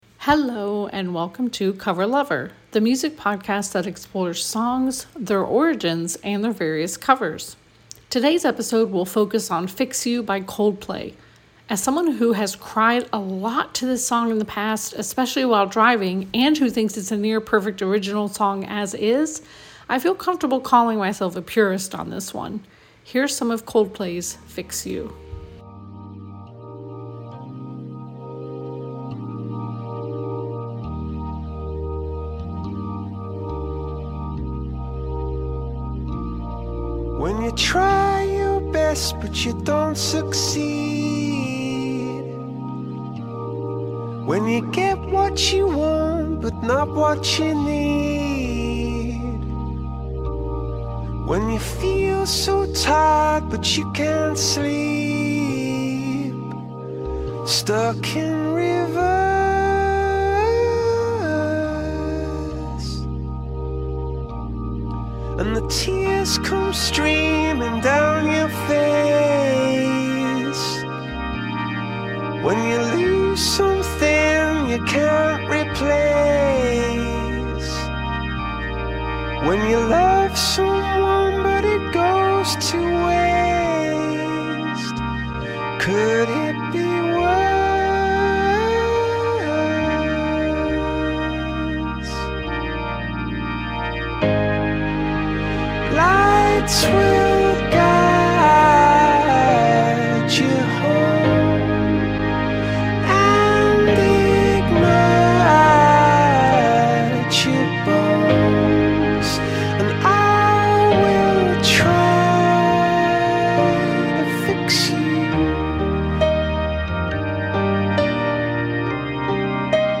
Be a guest on this podcast Language: en Genres: Music , Music Commentary Contact email: Get it Feed URL: Get it iTunes ID: Get it Get all podcast data Listen Now...